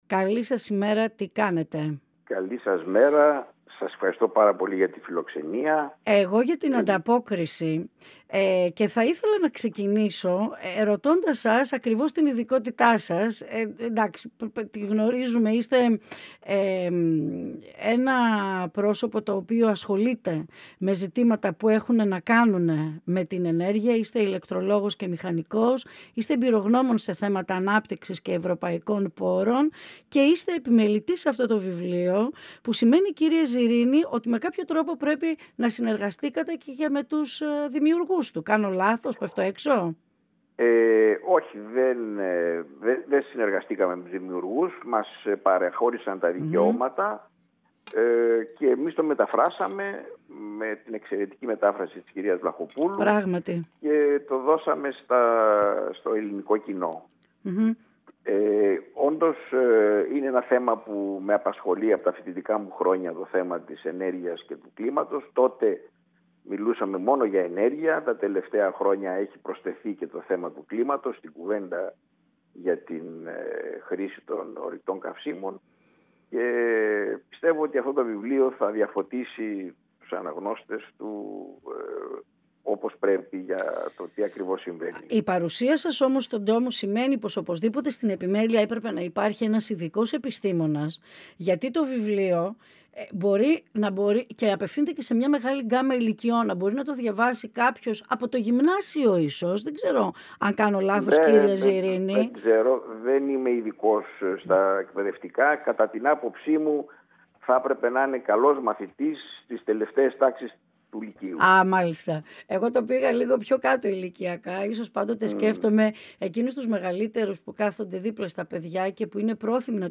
του γκράφικ νόβελ “Ένας κόσμος χωρίς τέλος” των Μπλεν και Ζανκοβισί που κυκλοφορεί από τις εκδόσεις Κριτική. 958FM Ολιγη Κινηση του Δρομου και των Μαγαζιων Συνεντεύξεις ΕΡΤ3